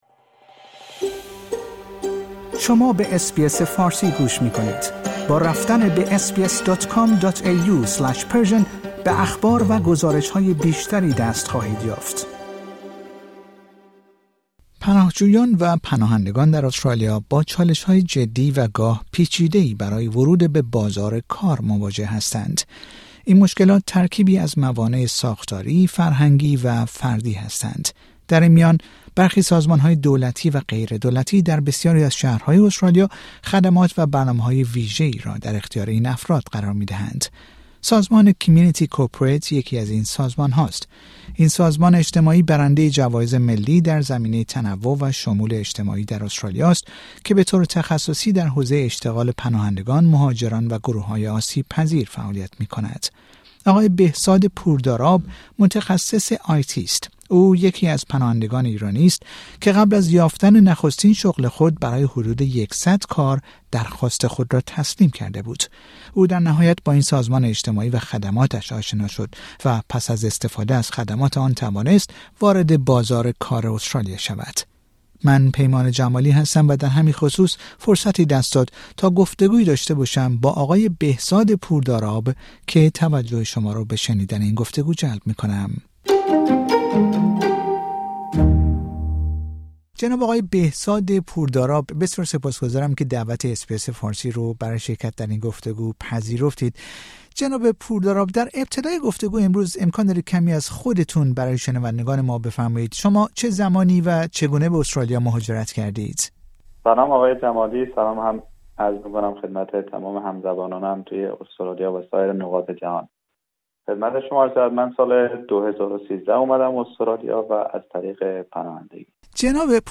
گفتگو با رادیو اس بی اس فارسی